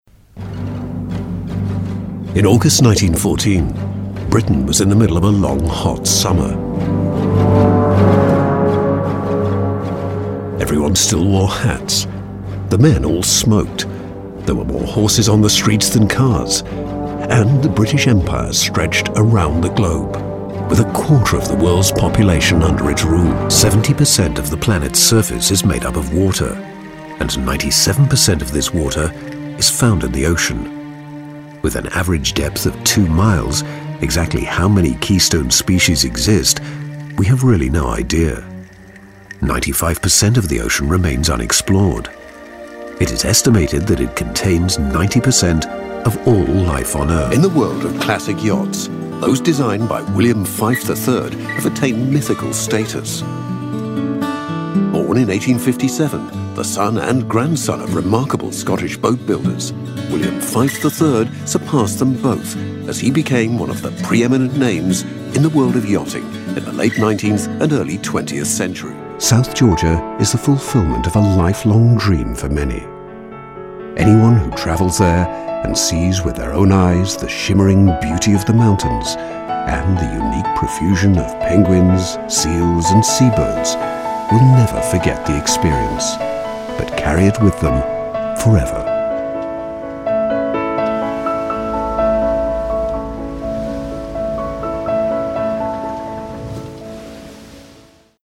Documentários
Minha voz é geralmente descrita como calorosa, natural e distinta e é frequentemente usada para adicionar classe e sofisticação a projetos de publicidade e narrativa.